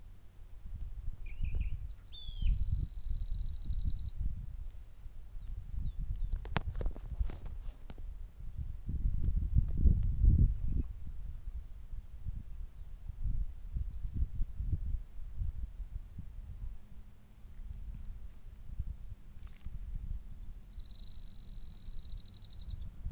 Canastero Rojizo (Asthenes dorbignyi)
Nombre en inglés: Rusty-vented Canastero
Fase de la vida: Adulto
Localidad o área protegida: Amaicha del Valle
Condición: Silvestre
Certeza: Fotografiada, Vocalización Grabada